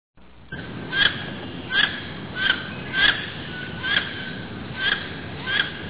Karolinka - Aix Sponsa
głosy